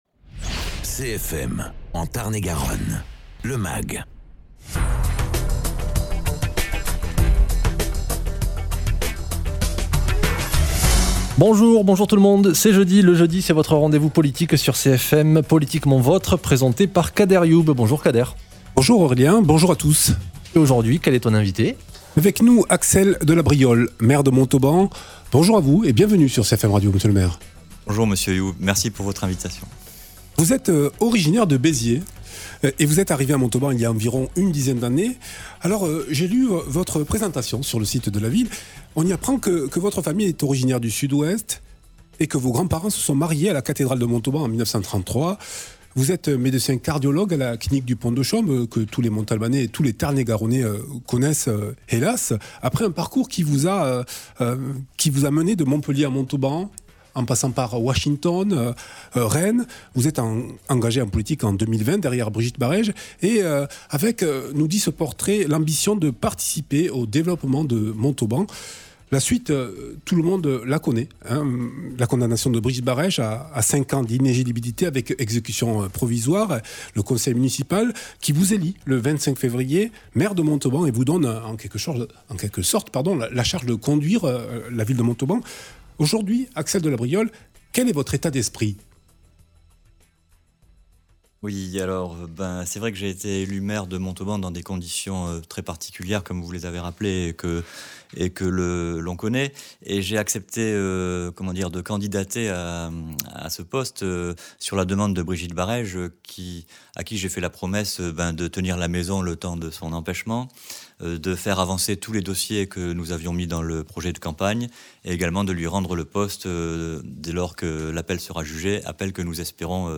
Invité(s) : Axel de Labriolle, maire de Montauban